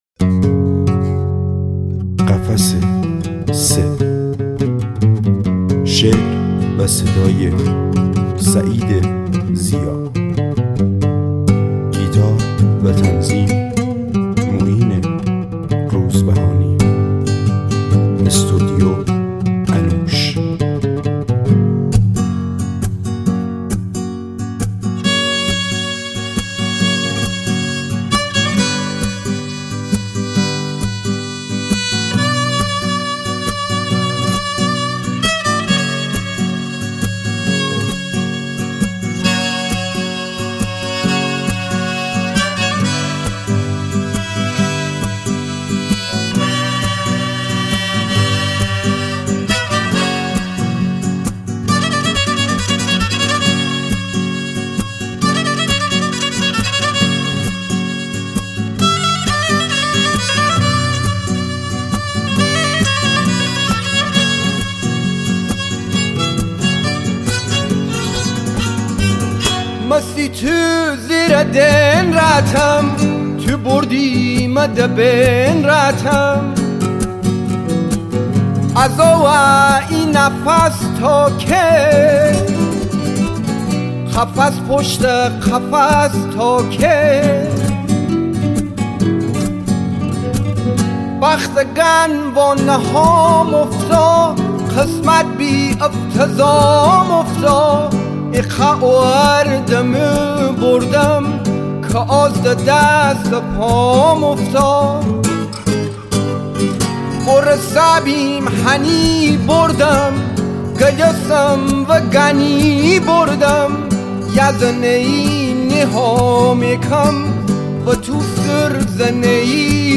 موسیقی لری